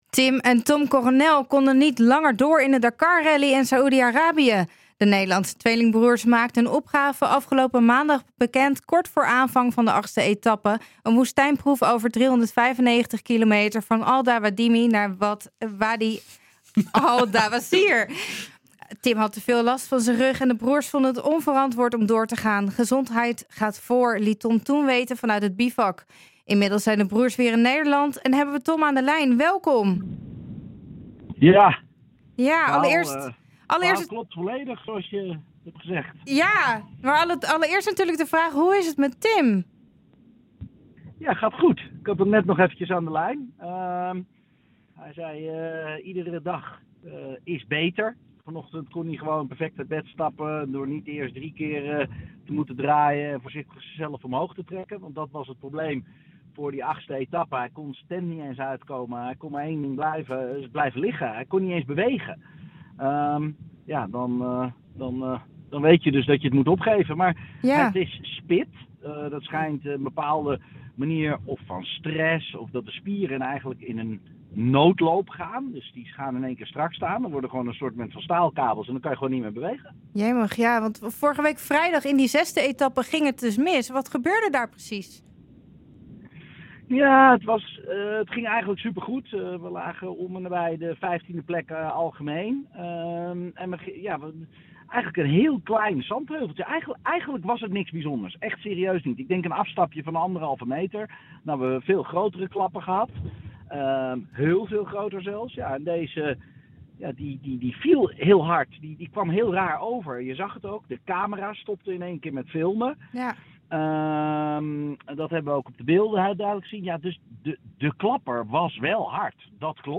Inmiddels zijn de broers weer in Nederland en hadden we Tom aan de lijn!